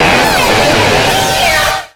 Cri de Noctunoir dans Pokémon X et Y.